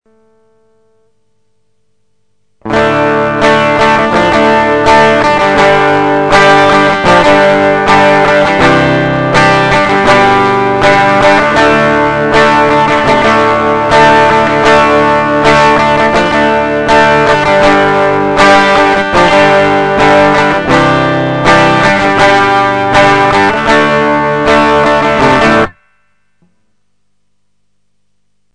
crunchmaxtubereverb.mp3